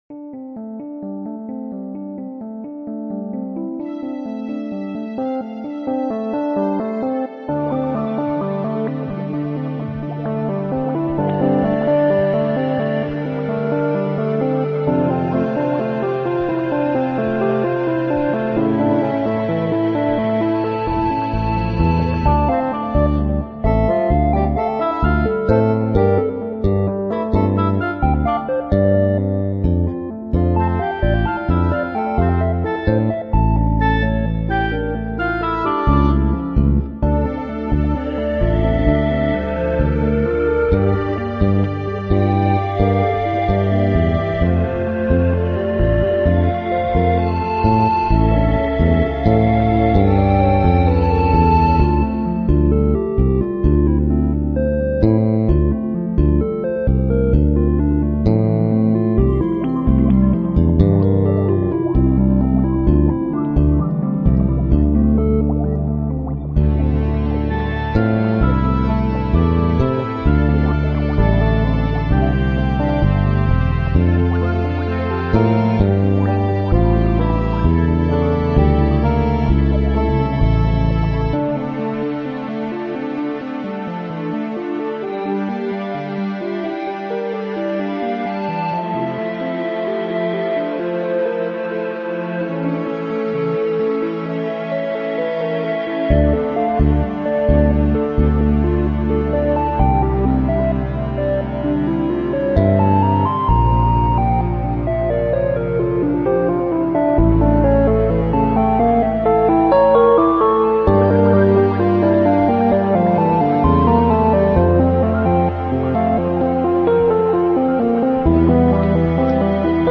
Relaxed Soundtrack Music with feel of Mystery and Magic